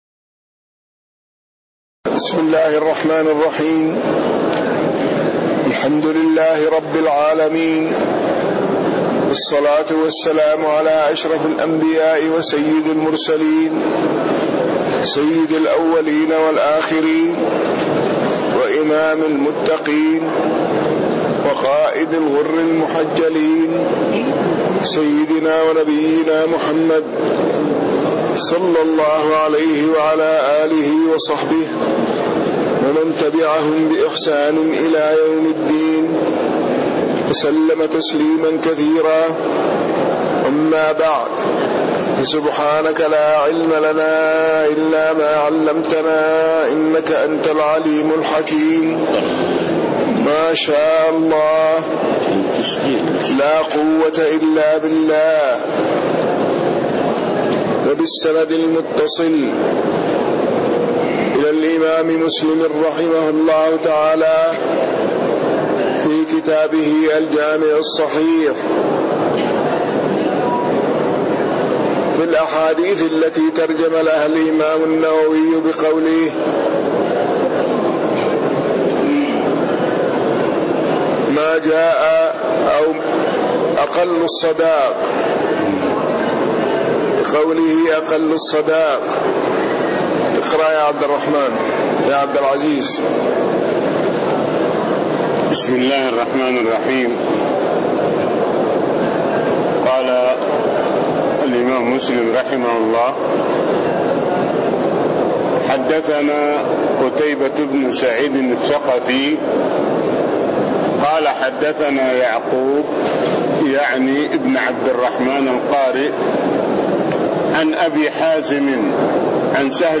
تاريخ النشر ١١ جمادى الآخرة ١٤٣٤ هـ المكان: المسجد النبوي الشيخ